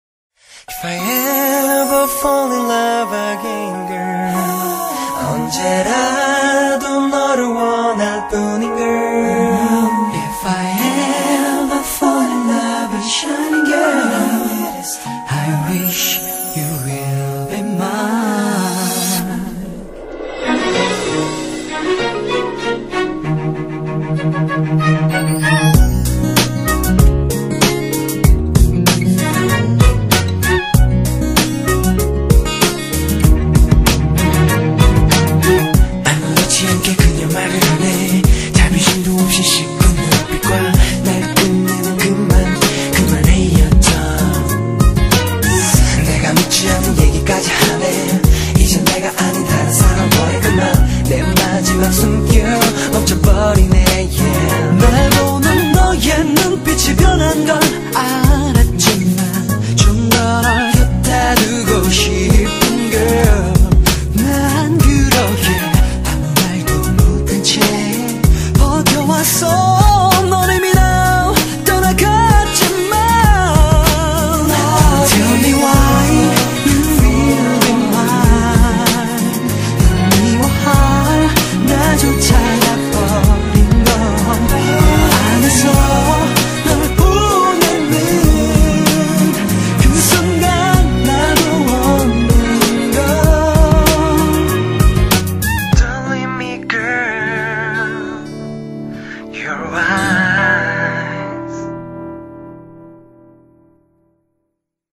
BPM96--1
Audio QualityPerfect (High Quality)